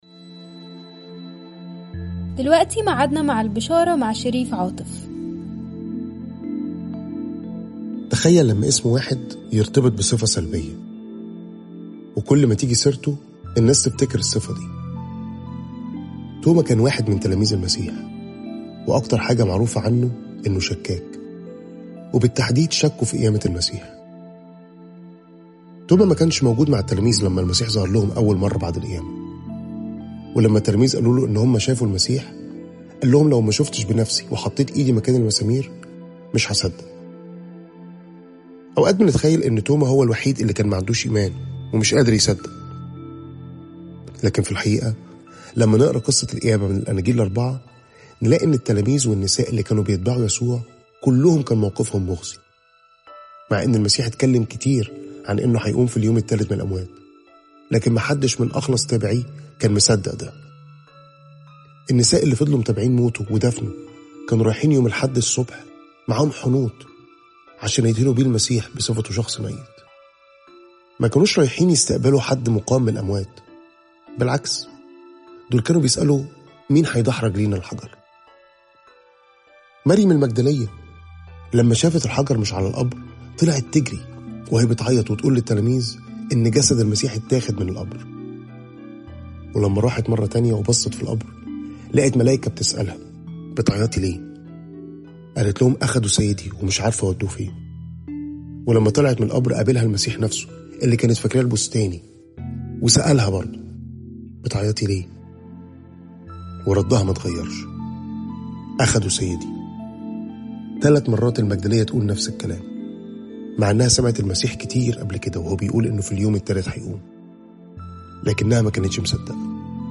تأملات مُركّزة باللهجة المصرية عن عُمق وروعة صليب المسيح وقيامته